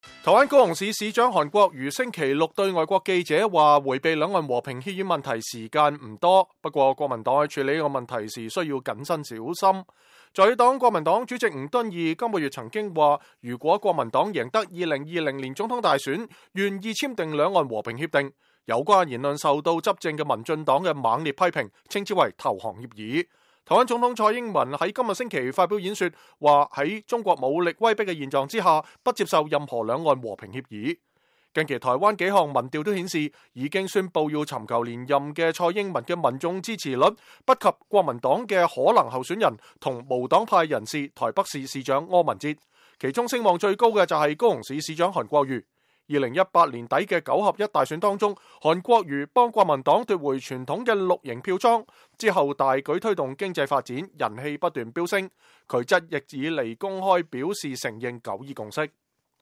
高雄市長韓國瑜接受外國傳媒訪問，他認為兩岸和平協議是一個越來越不可逃避的議題